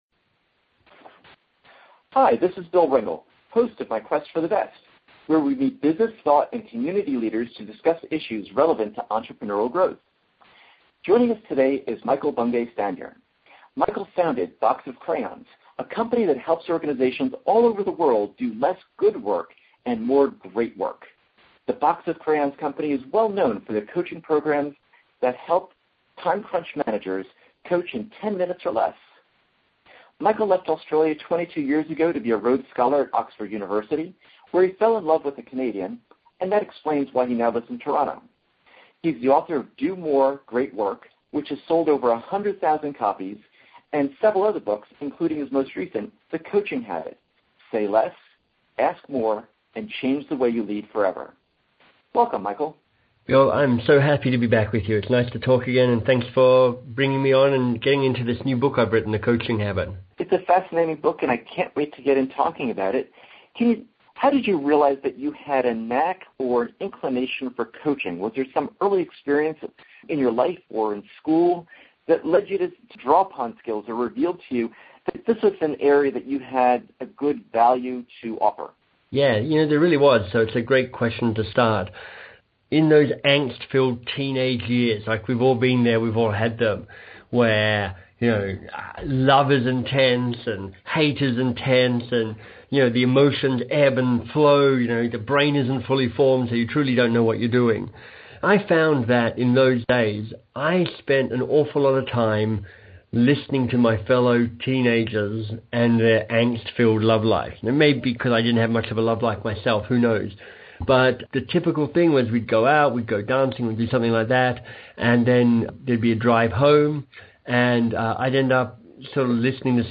Practical conversations with top business authors to help small business leaders grow faster, lead better, and apply smarter ideas to sales, culture, and strategy.